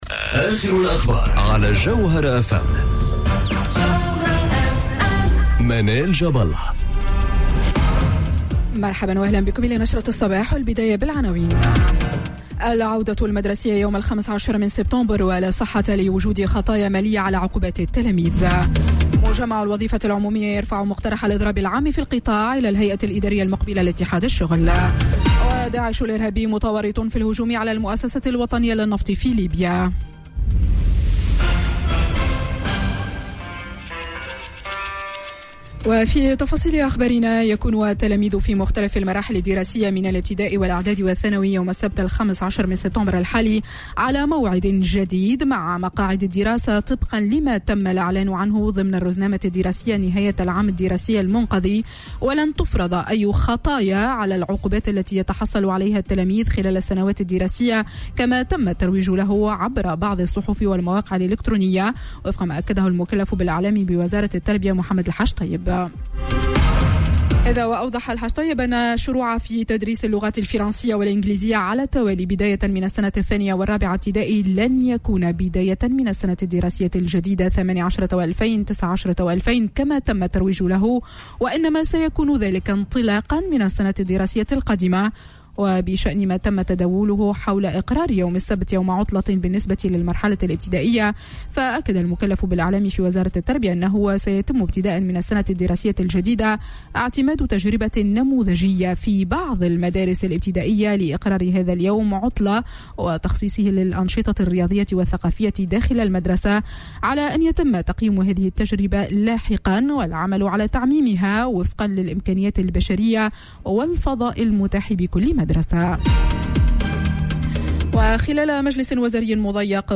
نشرة أخبار السابعة صباحا ليوم الثلاثاء 11 سبتمبر 2018